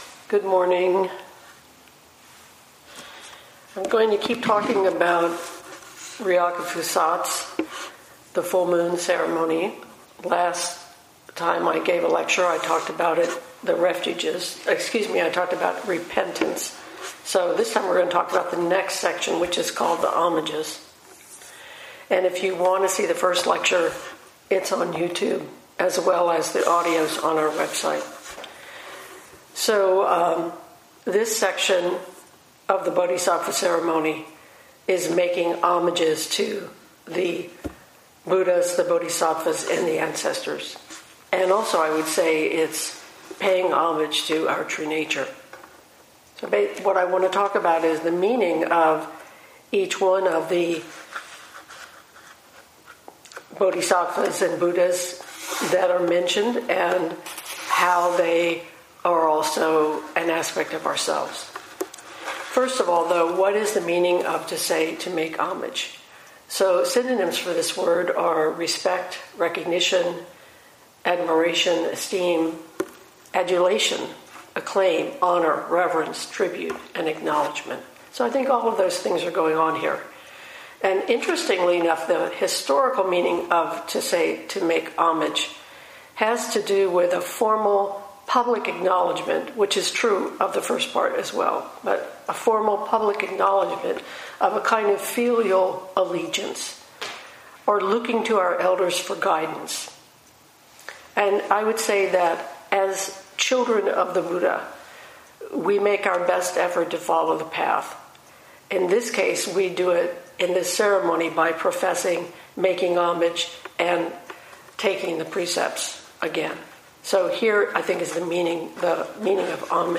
Full Moon Ceremony Talk 2: Homages
2020 in Dharma Talks